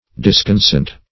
Search Result for " disconsent" : The Collaborative International Dictionary of English v.0.48: Disconsent \Dis`con*sent"\ (d[i^]s`k[o^]n*s[e^]nt"), v. i. To differ; to disagree; to dissent.
disconsent.mp3